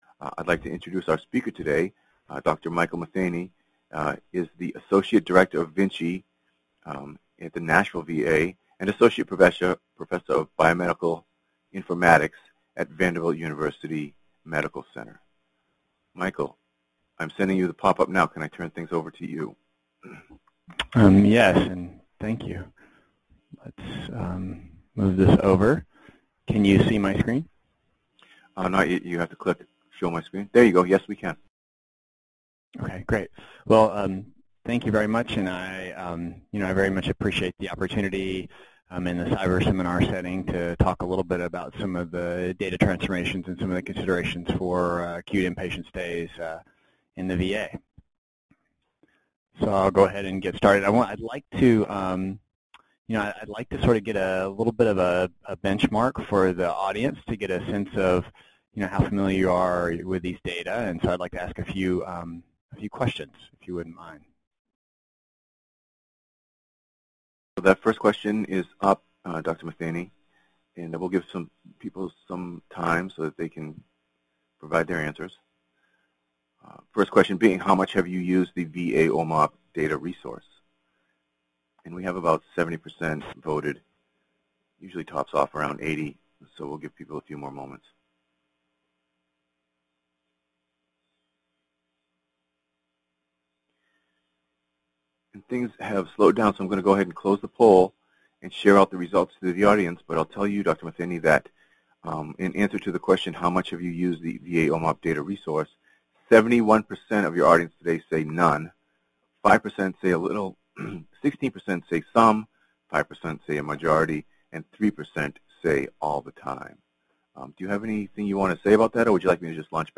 Description: This seminar will focus on transforming the CDW Inpatient domain to identify acute inpatient stays, discuss multiple reference groups' key recommendations for utilizing this data, and describe the OMOP implementation and how to utilize and link to the Visit Occurrence table in OMOP for acute inpatient stay information.